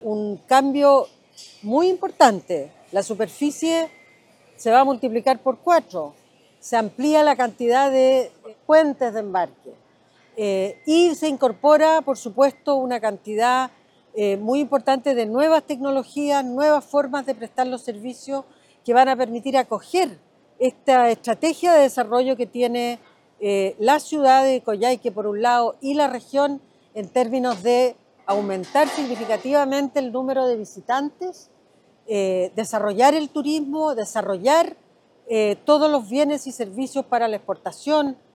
Jessica López Saffie / Ministra MOP